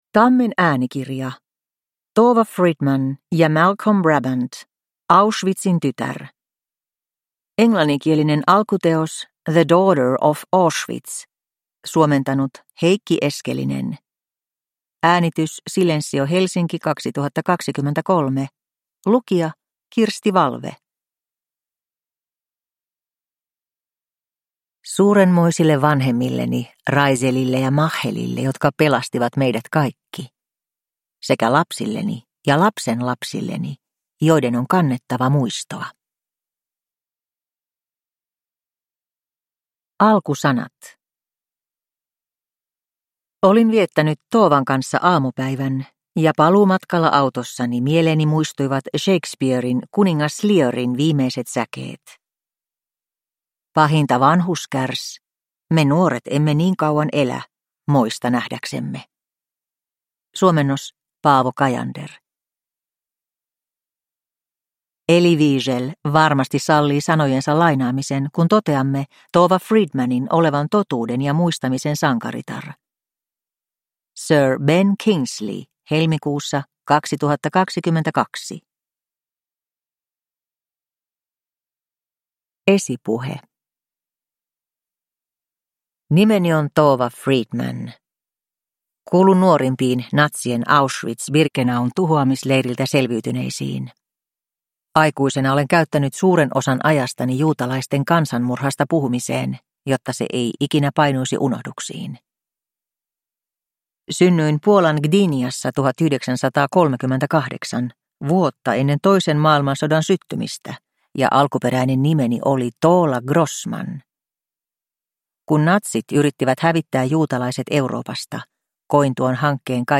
Auschwitzin tytär – Ljudbok – Laddas ner